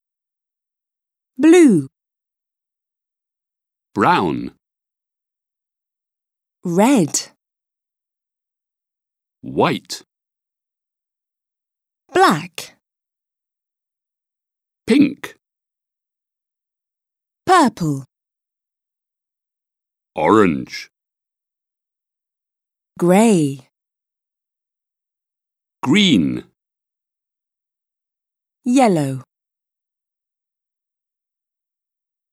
• Prononciation des couleurs en anglais